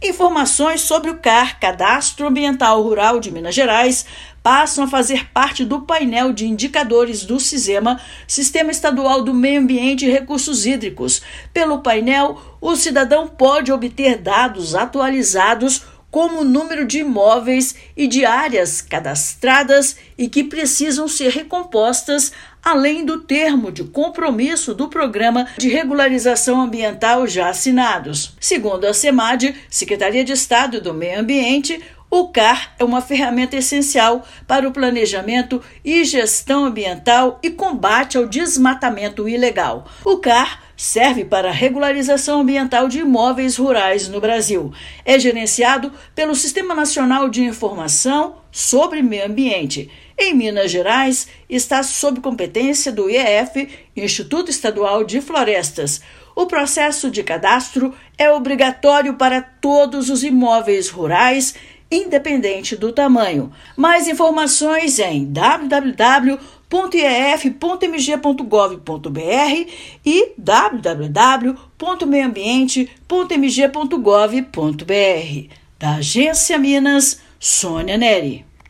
[RÁDIO] Governo de Minas integra dados do Cadastro Ambiental Rural ao Painel de Indicadores do Meio Ambiente
Medida reforça compromisso com transparência e facilita acompanhamento da implementação das políticas ambientais no estado. Ouça matéria de rádio.